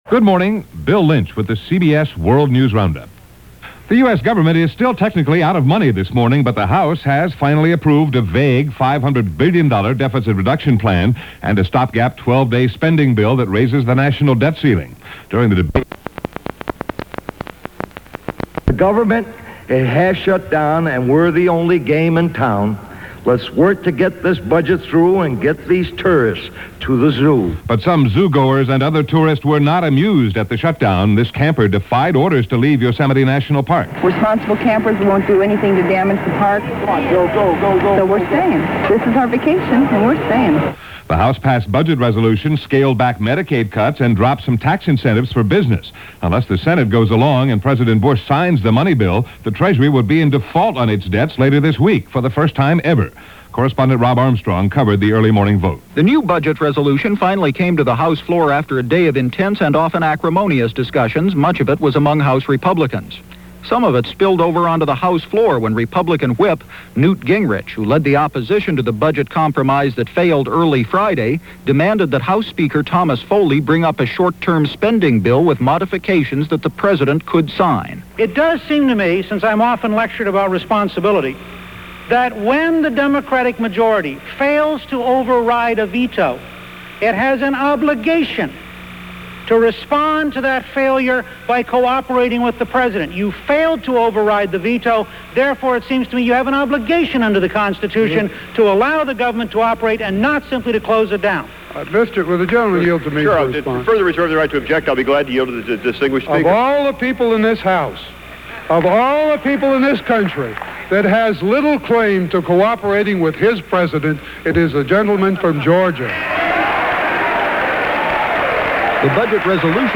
News-for-October-8-1990.mp3